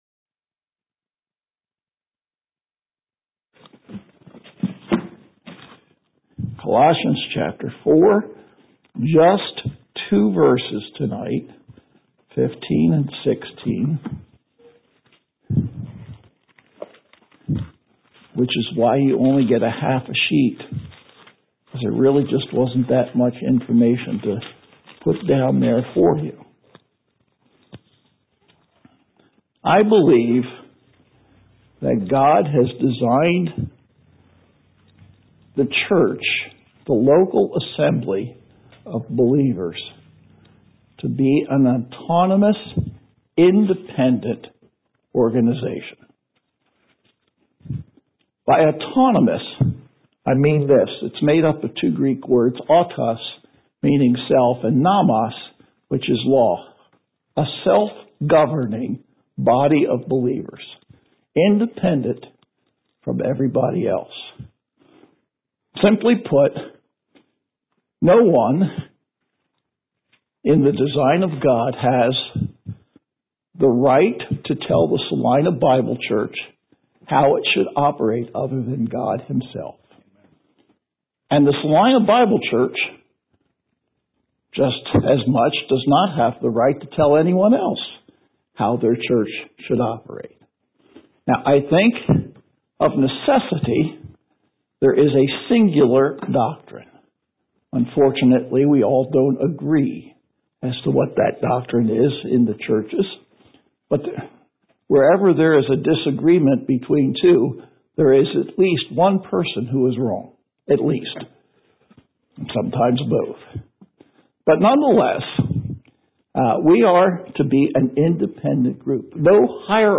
Worship Messages